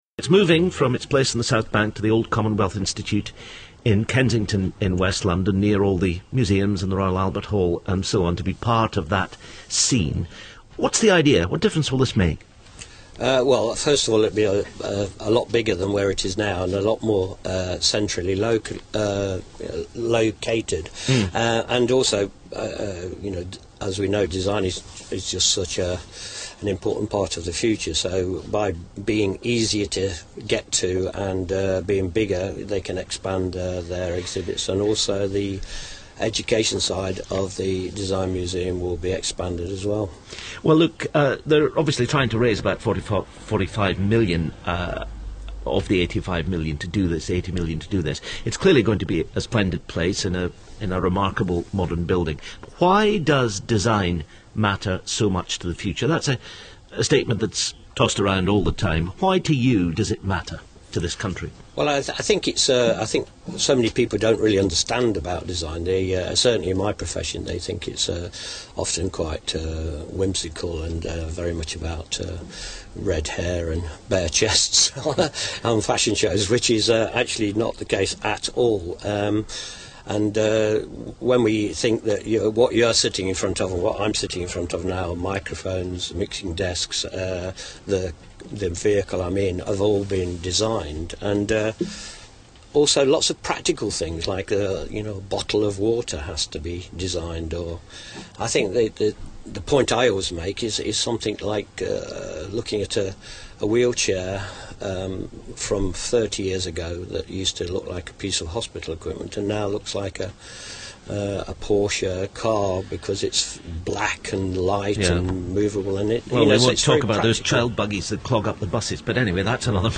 Paul Smith, the fashion designer, was interviewed on the Today Programme on 24 January 2012 to discuss the significance of the Design Musuem’s move from the South Bank to Kensington (along with the other key national museums including the V&A). Whilst he was not electrifying, he does give a sense of what design is and why it is important.